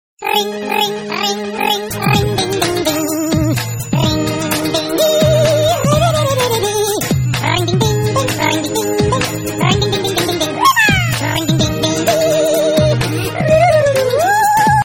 نغمة مضحكة